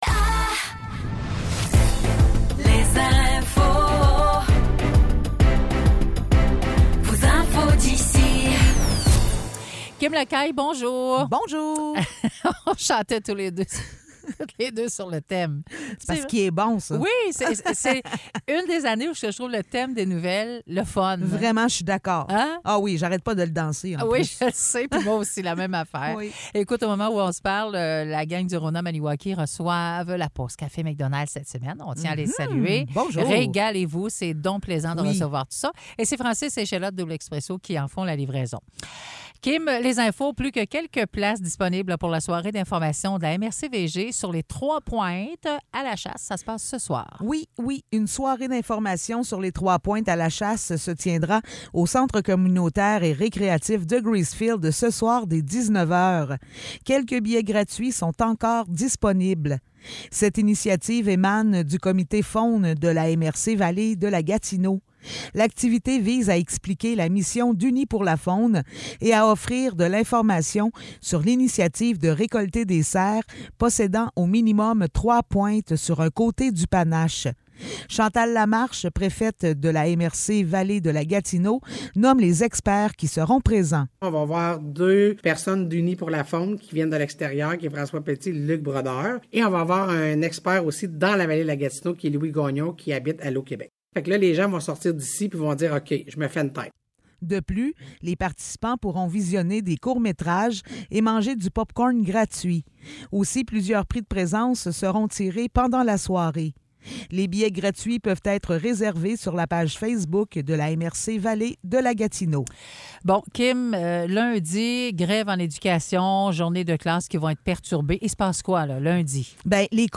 Nouvelles locales - 3 novembre 2023 - 10 h